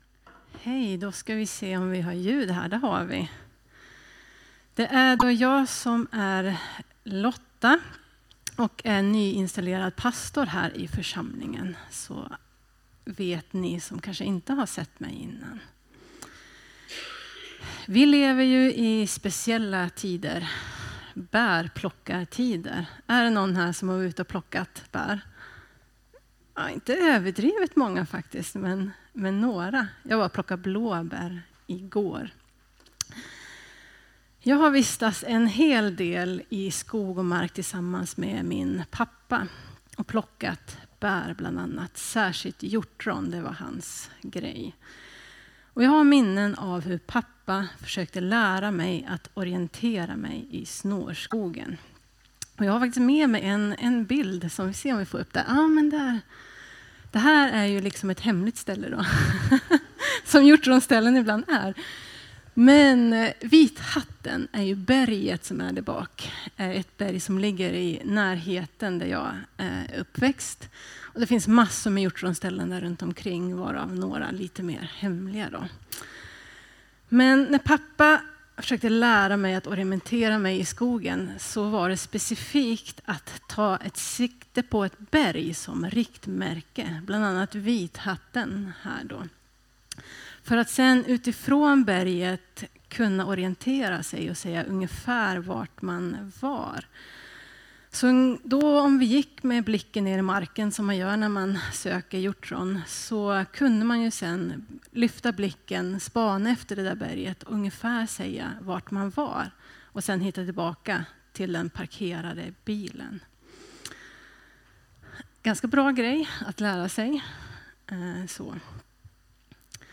Predikningar Elimkyrkan Gammelstad